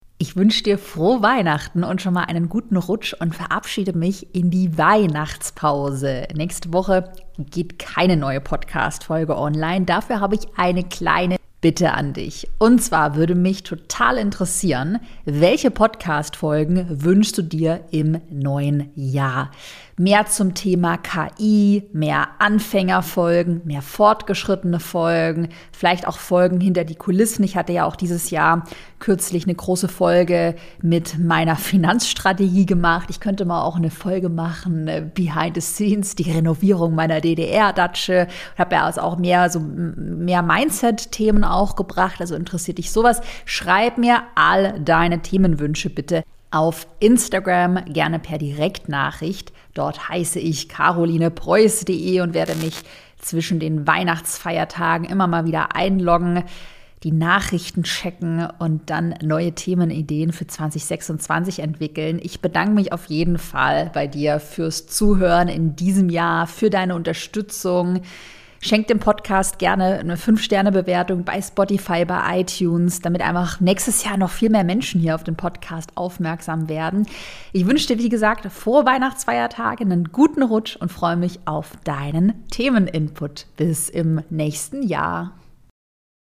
Diese Folge ist eine kurze Durchsage in eigener Sache.